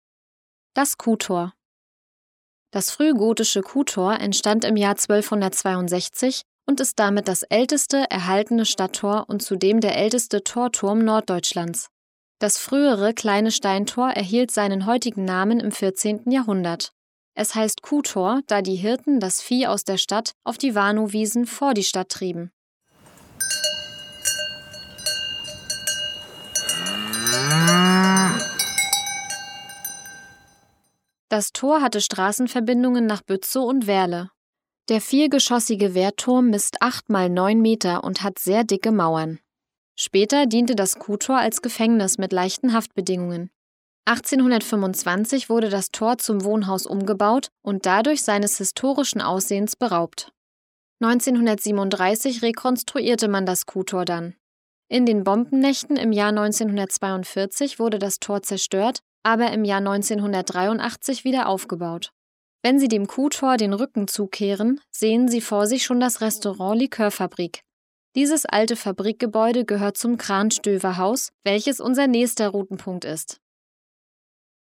Audioguide Rostock - Station 13: Kuhtor